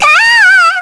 May-Vox_Damage_kr_02.wav